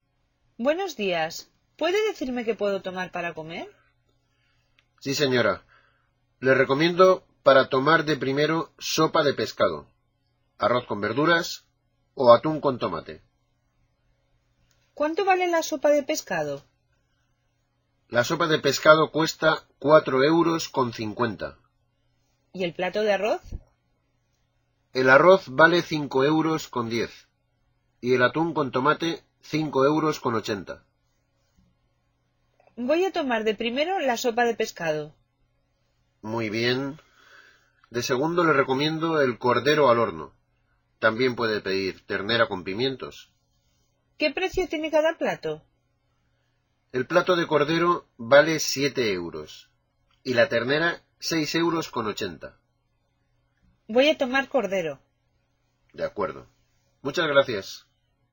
Listen to a conversation between a waiter and a customer.